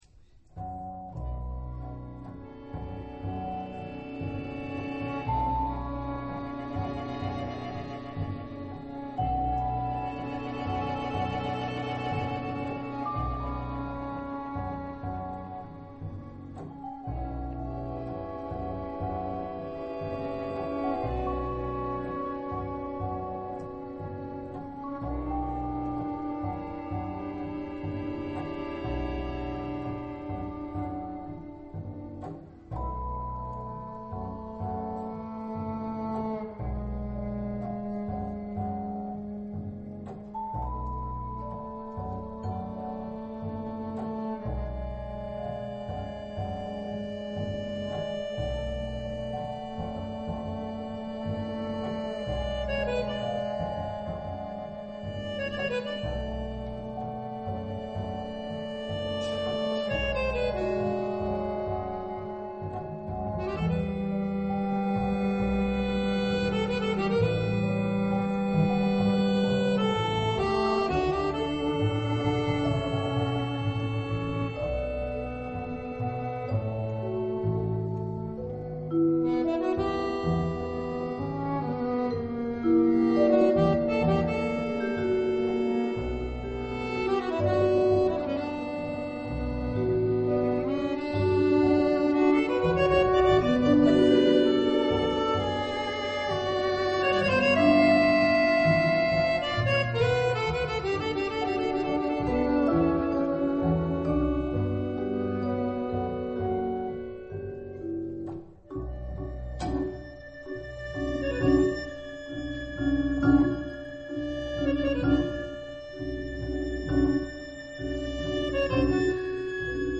le new tango de Buenos Aires. vidéo
VIOLON Né en Pologne.
VIBRAPHONE Né en Suisse.
ACCORDÉON Né en France.
CONTREBASSE Né en Suisse.